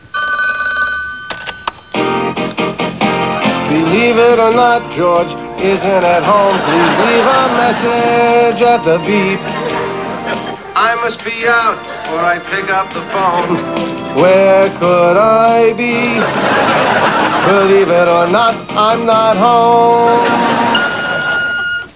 George's answer machine-